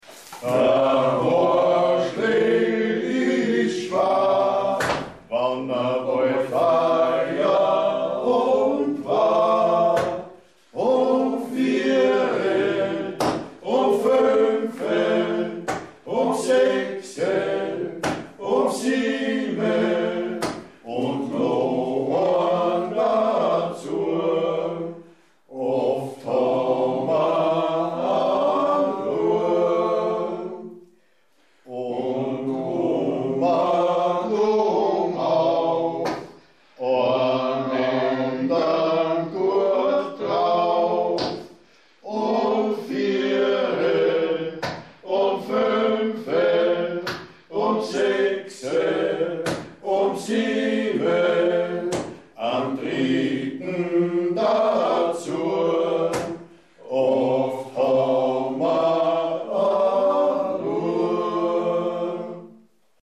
(Texte und Gesangsproben).
Da Hoarschlögl (Liedertafel Gusswerk)